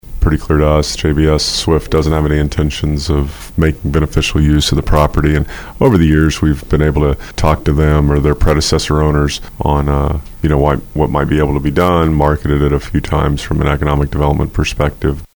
City Manager Matt Allen says there are some questions about water rights, but officials are comfortable with the pricetag of 2.5 million dollars.